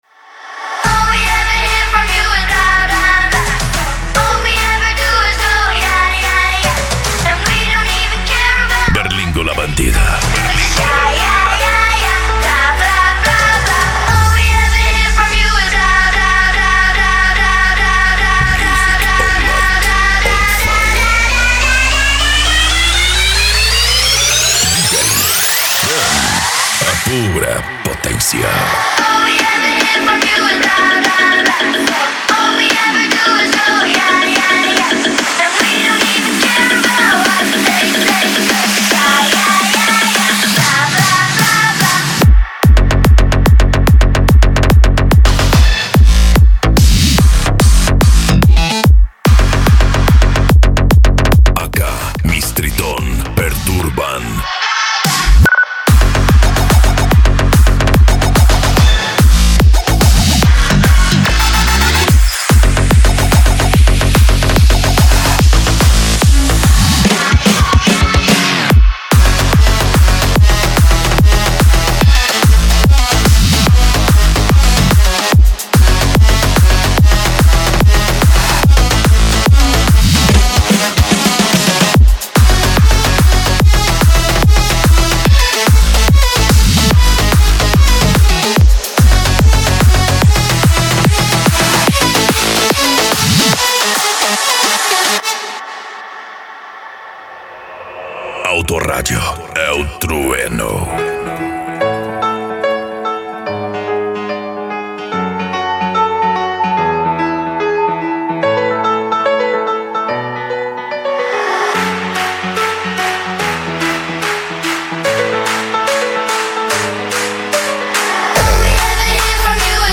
Bass
PANCADÃO
Psy Trance
Remix